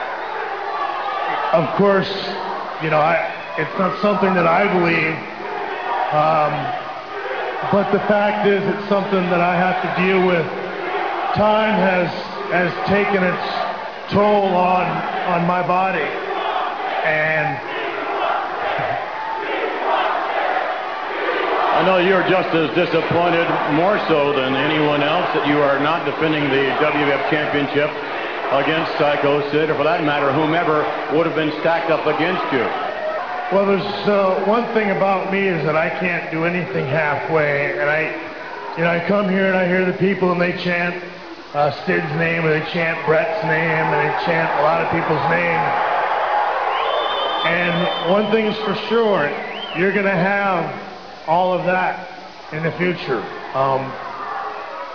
they loudly began to chant “We Want Bret” and “We Want Sid.